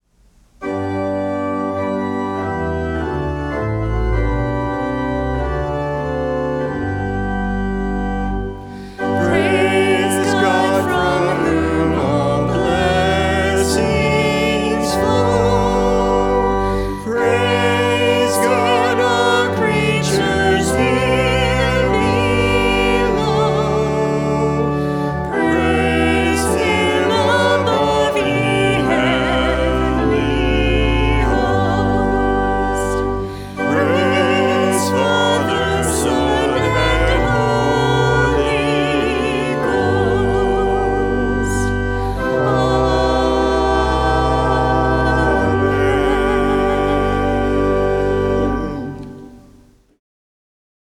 Service of Worship
Doxology